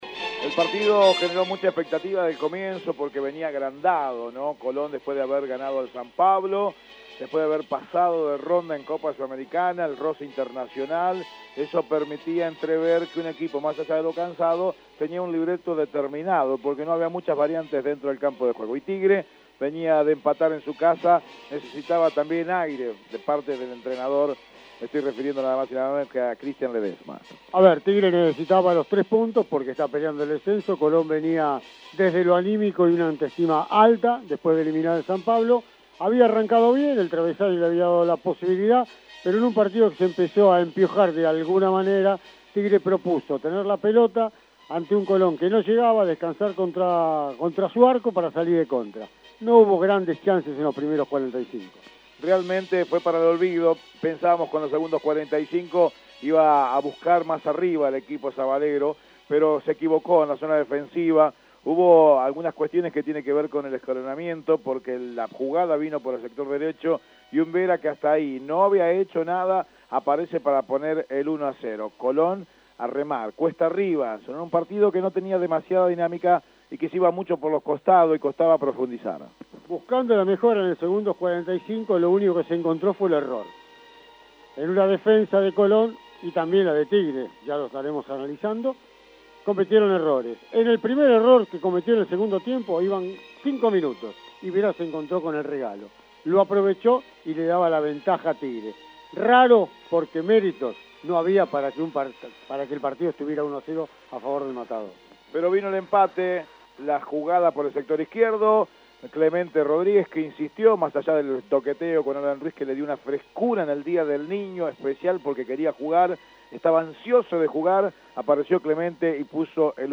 • Resumen del partido